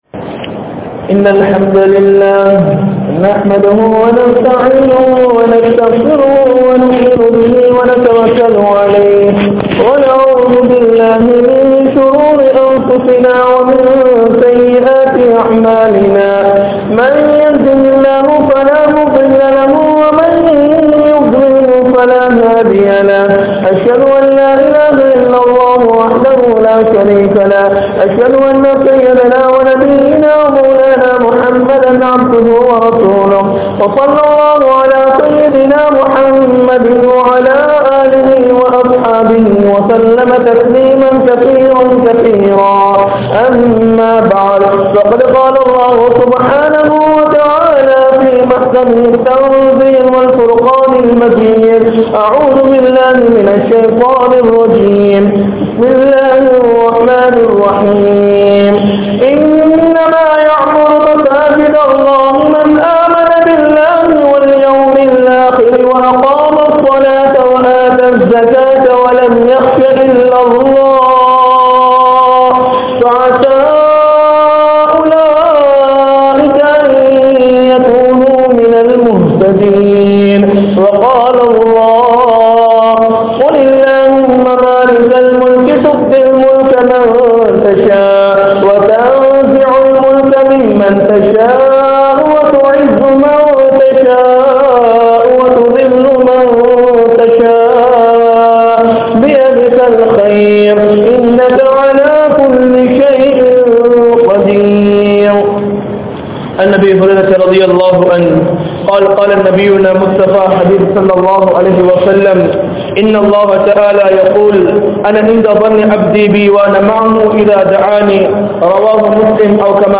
Padaiththavanai Marantha Samooham (படைத்தவனை மறந்த சமூகம்) | Audio Bayans | All Ceylon Muslim Youth Community | Addalaichenai
Majma Ul Khairah Jumua Masjith (Nimal Road)